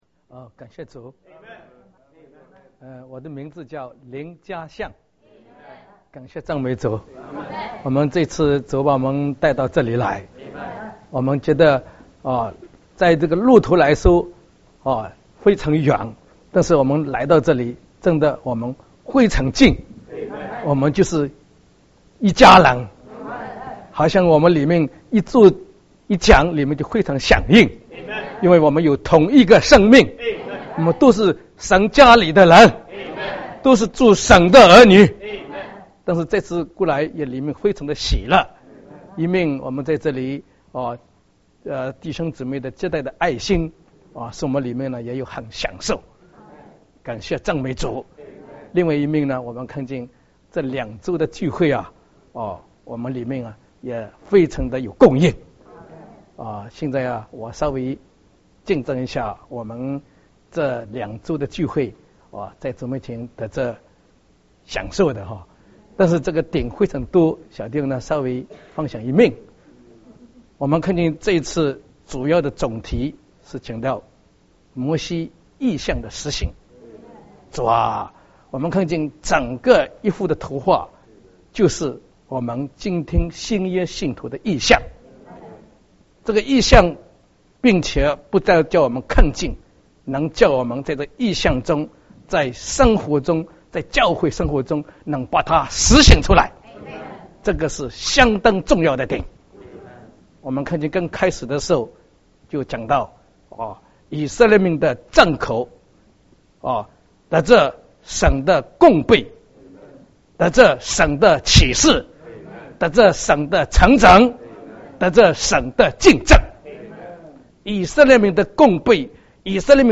2013.6.8 见证分享聚会
见证：圣徒分享见证，96分钟，下载mp3文档 （右键点击下载）